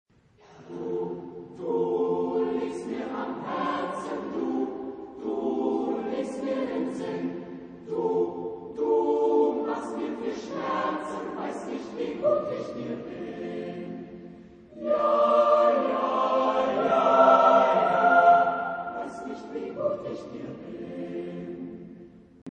Genre-Style-Forme : Folklore ; Profane
Type de choeur : SATB  (4 voix mixtes )
Tonalité : fa majeur
interprété par Knabenchor Frankfurt (Oder)
Réf. discographique : 7. Deutscher Chorwettbewerb 2006 Kiel